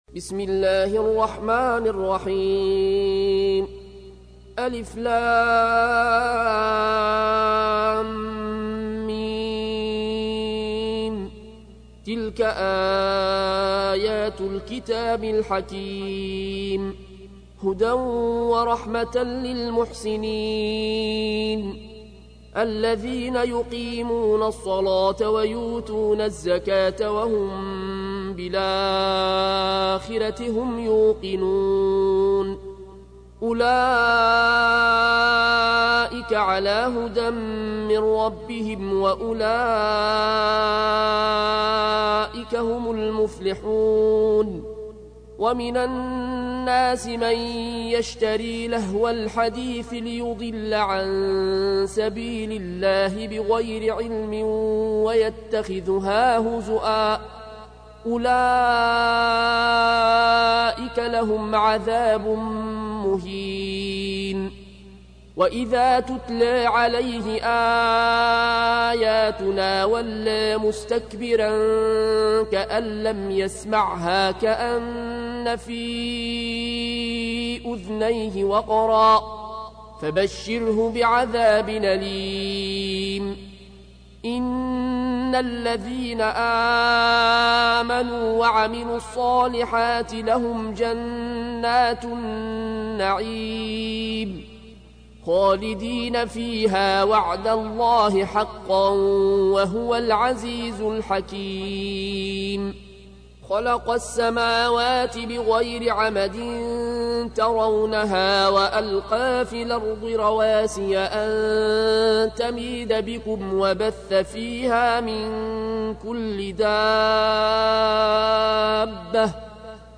تحميل : 31. سورة لقمان / القارئ العيون الكوشي / القرآن الكريم / موقع يا حسين